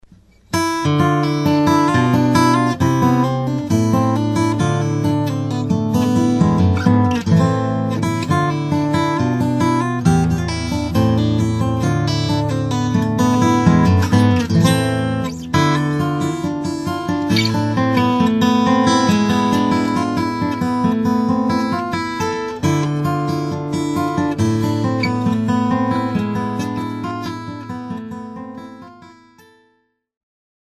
hammer-ons, pull-offs and legato style playing